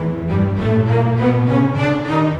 Rock-Pop 20 Bass, Cello _ Viola 02.wav